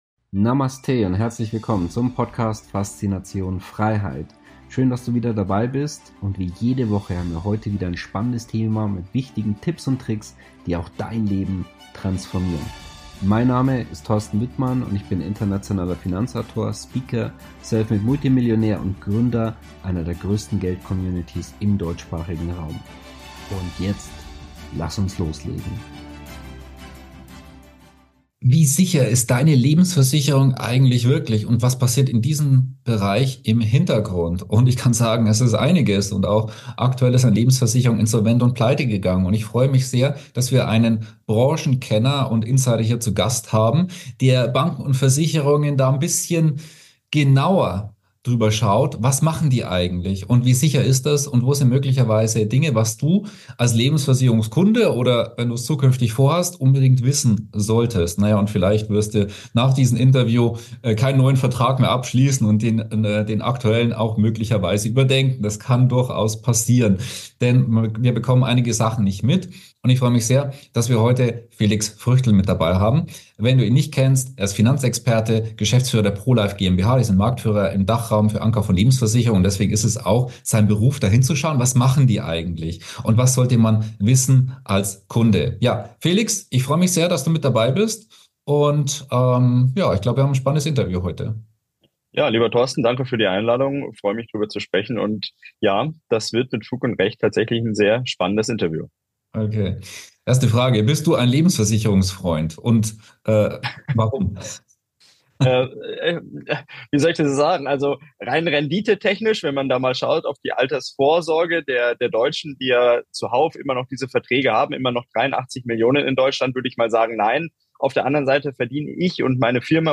Die diskutierten Punkte im Interview gelten übrigens auch für Österreicher und Schweizer Versicherer.